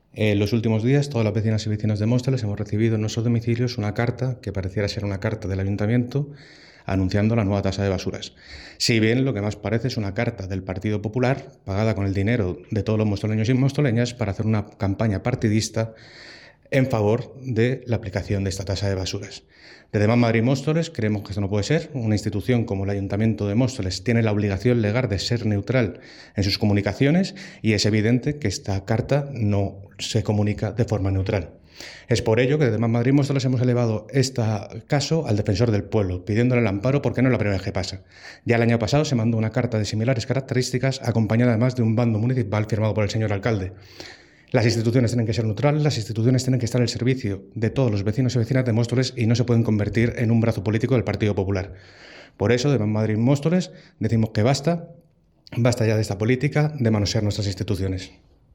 Jesús Arrabé, concejal de Más Madrid Móstoles, ha expresado que «estas actuaciones suponen una vulneración del derecho de los vecinos y vecinas a recibir información institucional neutral y dañan la confianza en las instituciones públicas».
declaraciones-jesus-arrabe-carta-basuras.mp3